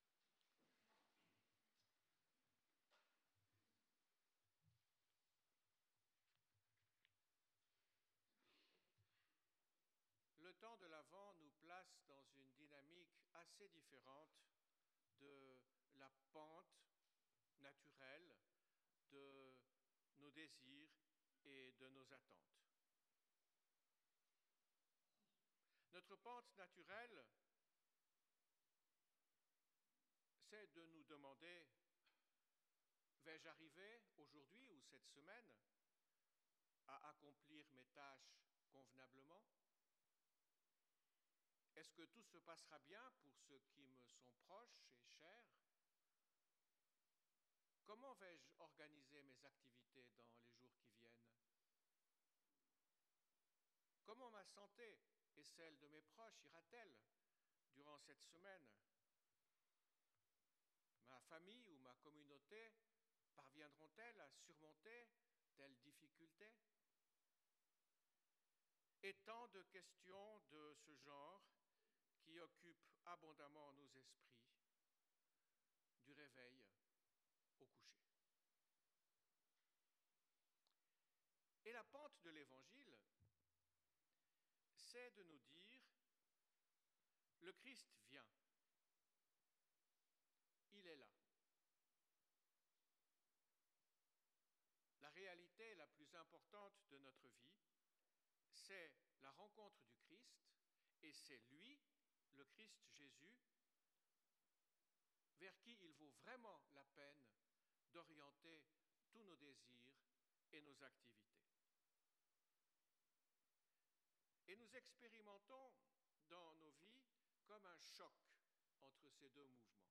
Pour ce dimanche de la deuxième semaine de l'Avent
Nous nous excusons pour la qualité de cet enregistrement. Il est audible si l'on utilise des écouteurs et que l'on augmente le volume.